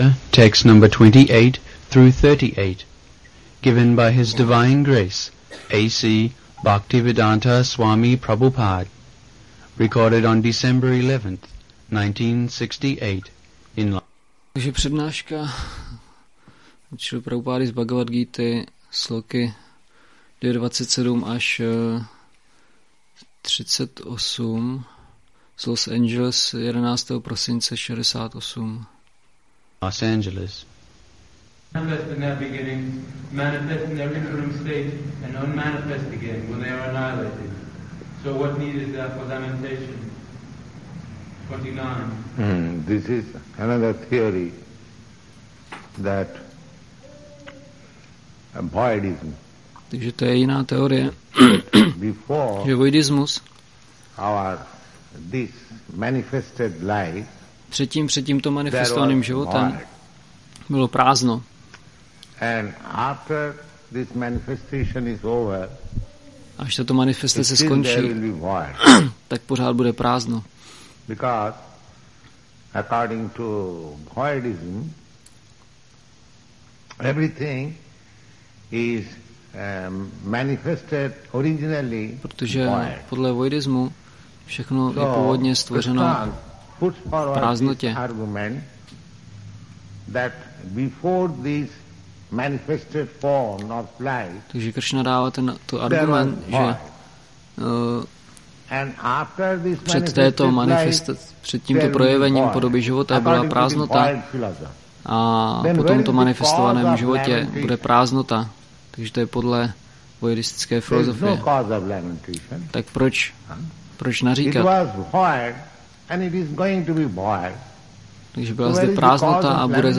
1968-12-11-ACPP Šríla Prabhupáda – Přednáška BG-2.27-38 Los Angeles